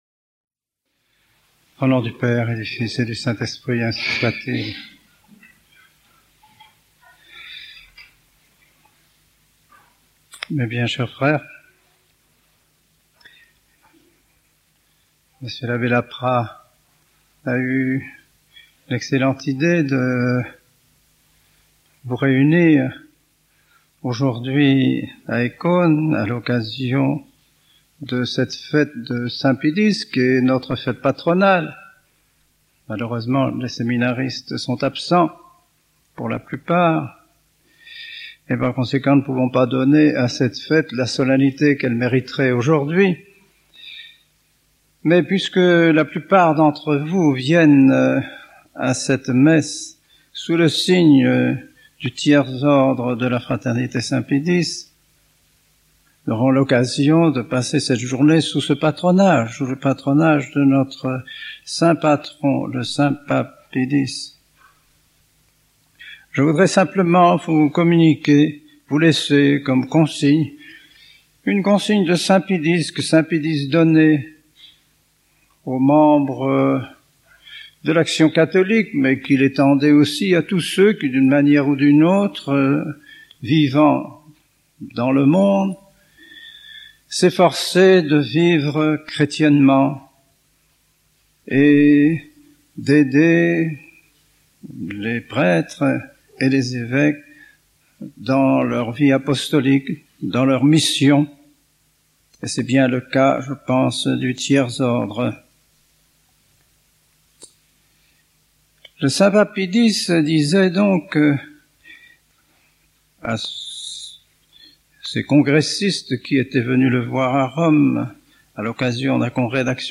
Texte de l’homélie
Sermon aux Tertiaires de la Fraternité Saint Pie X 3 septembre 1988